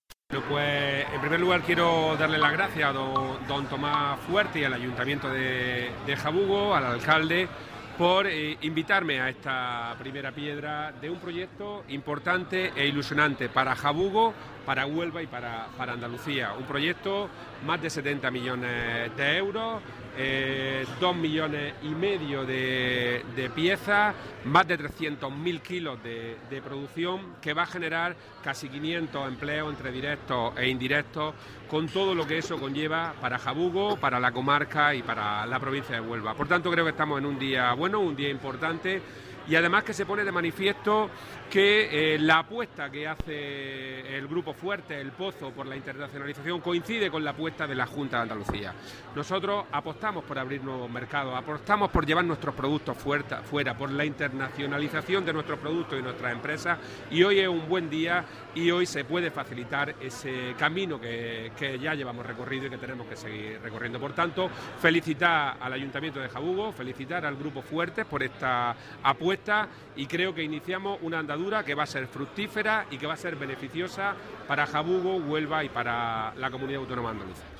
Sánchez Haro ha asistido al acto de colocación de la primera piedra de esta edificación, durante el cual ha felicitado al Grupo Fuertes, del que forma parte esta entidad, por su “acertada decisión” de levantar estas instalaciones en Jabugo.
Audio declaraciones Consejero